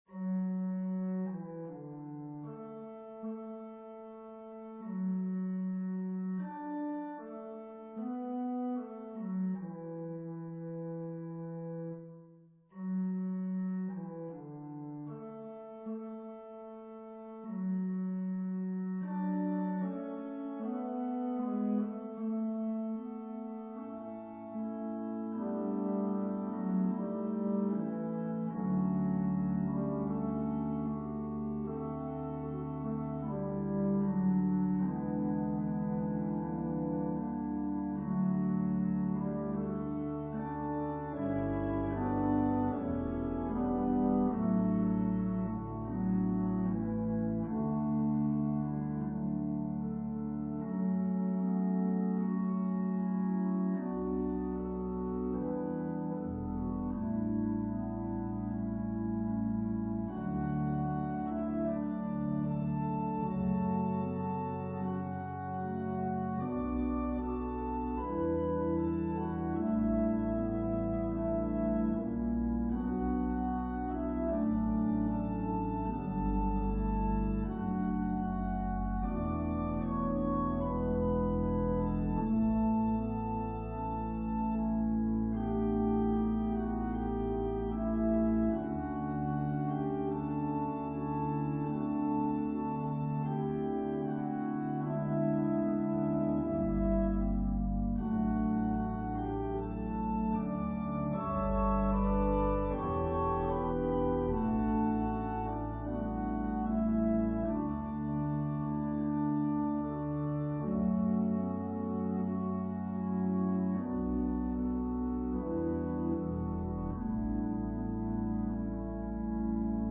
An organ solo version of Mack Wilberg's choral arrangement.
Voicing/Instrumentation: Organ/Organ Accompaniment We also have other 9 arrangements of " Sing We Now at Parting ".